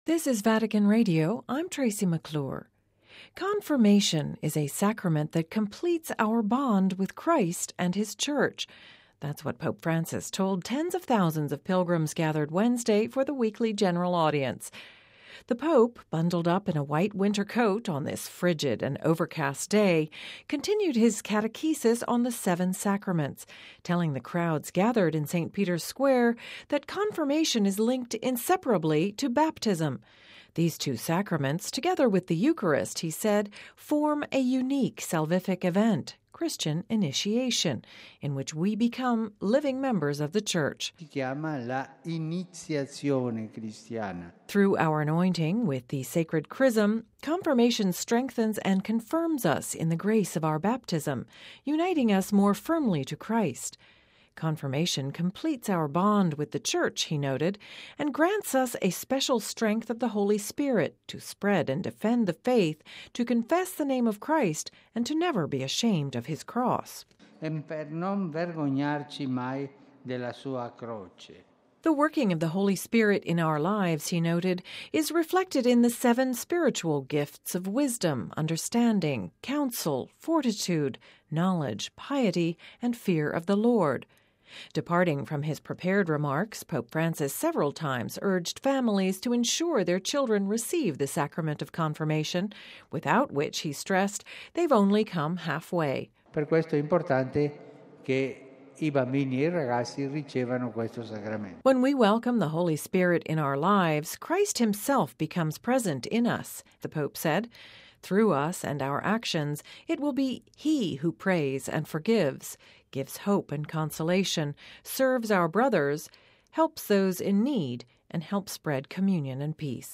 (Vatican Radio) Confirmation is a sacrament that completes our bond with Christ and His Church: that’s what Pope Francis told tens of thousands of pilgrims gathered Wednesday for the weekly General Audience.